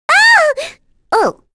May-Vox_Dead_b.wav